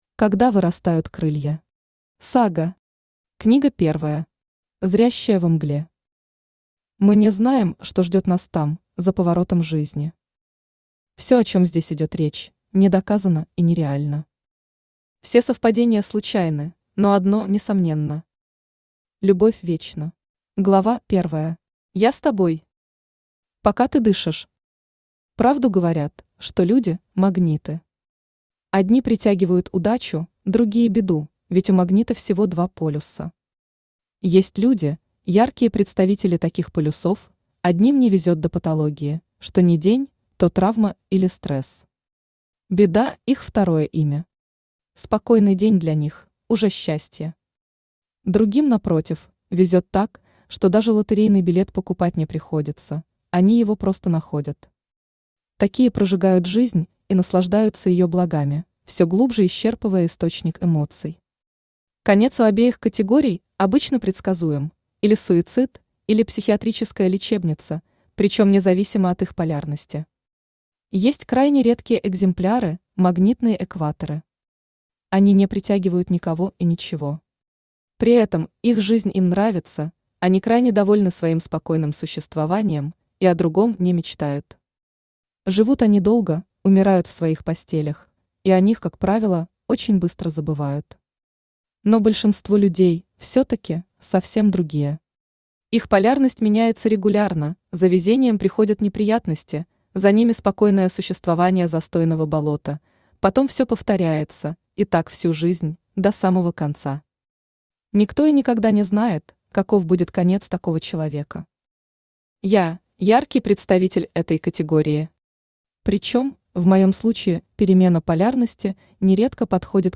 Аудиокнига Сага. Когда вырастают крылья. Книга I. Зрящая во мгле | Библиотека аудиокниг
Книга I. Зрящая во мгле Автор Лариса Черногорец Читает аудиокнигу Авточтец ЛитРес.